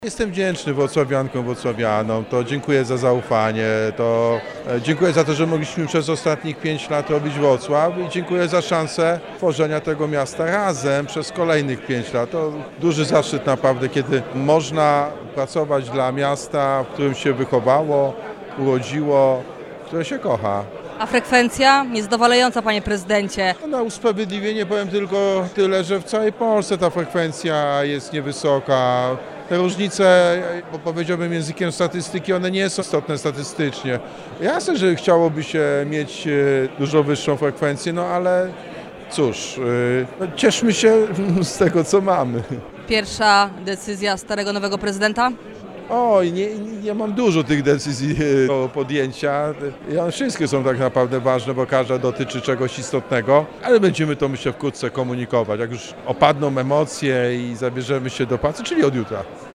-Jestem wdzięczny wrocławianom i wrocławiankom – mówił dla Radia Rodzina obecny gospodarz stolicy regiony – Jacek Sutryk.